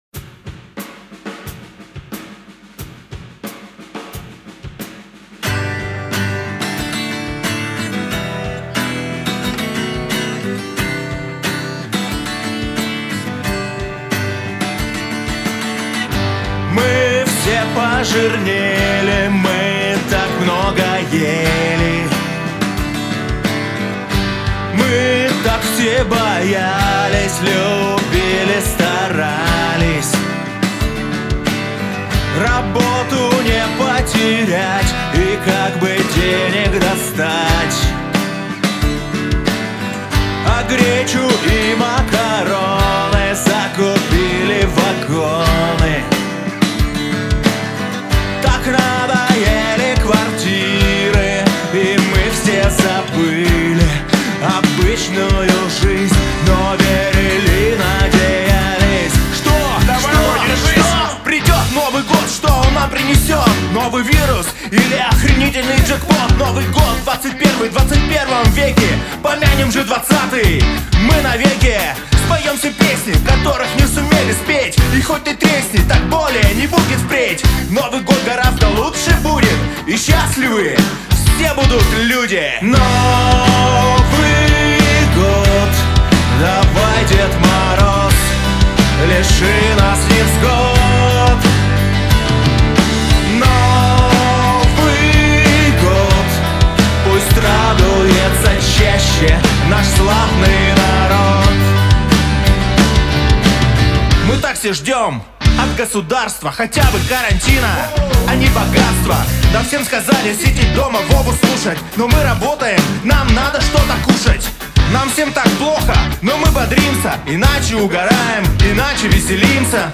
Поп-рок с элементами рэпа
Сварганил вот такую вот "коронавирусную" тему. Заказчик вдохновлялся чем-то вроде Нойза МС, требования были следующие: - В интро сделать постепенное нарастание барабанов из одних рум-микрофонов - Вокала больше, ещё больше, ещё! и чтобы максимально сухой - Побольше баса Как в целом?